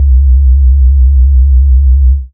Sub Wobble C3.wav